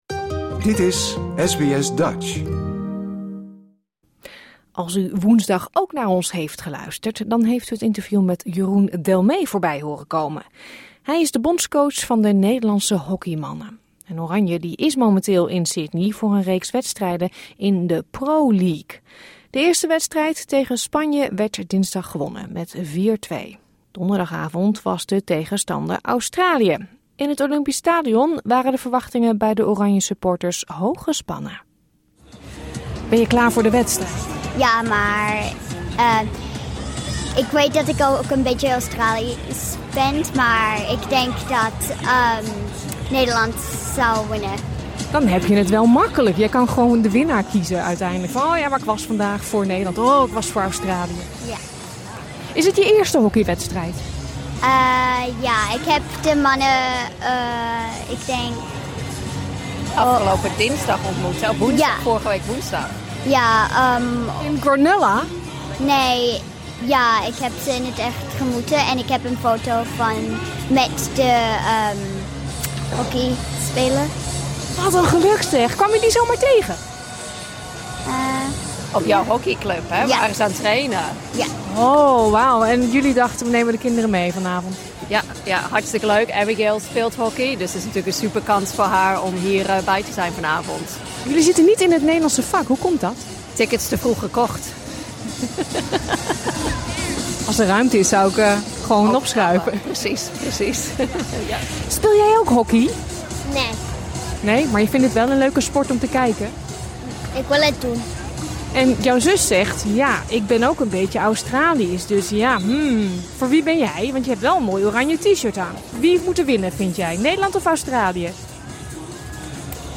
Donderdag speelden de Nederlandse hockeyers op Sydney Olympic Parc tegen Australië.
peilden we de stemming onder de oranjesupporters.